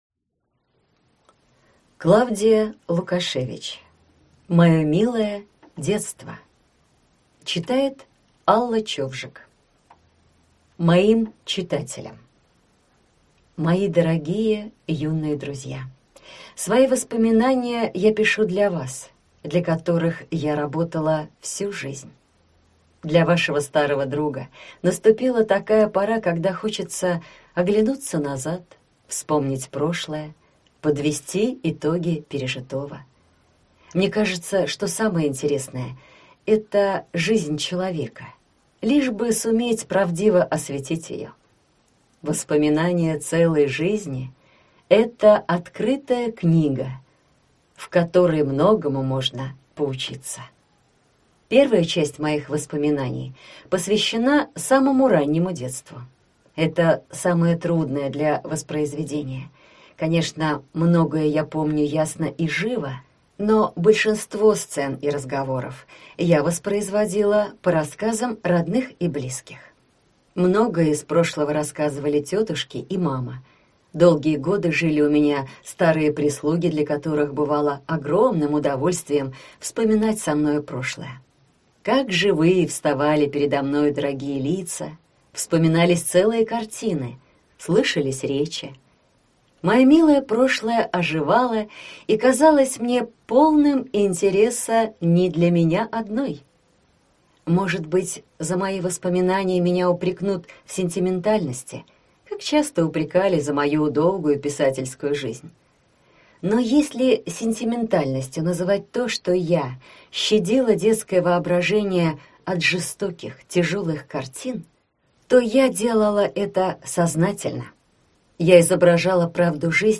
Аудиокнига Мое милое детство | Библиотека аудиокниг